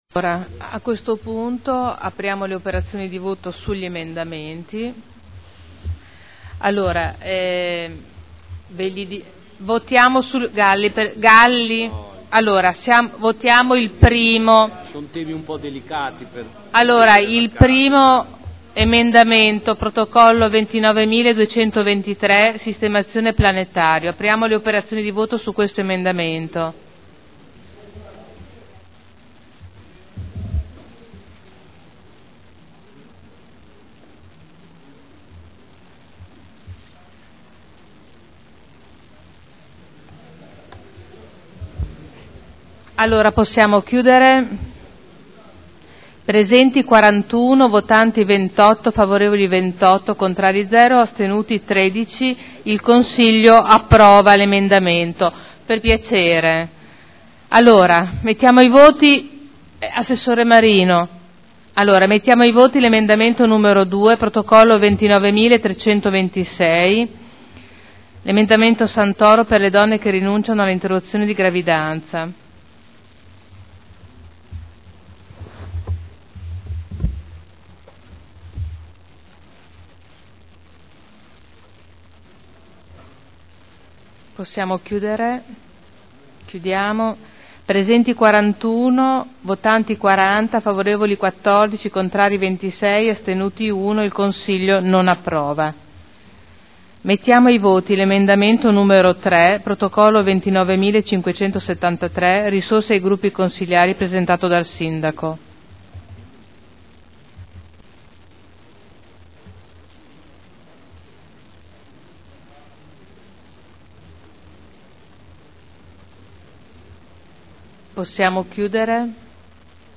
Seduta del 28/03/2011. Il Presidente Caterina Liotti mette ai voti gli emendamenti.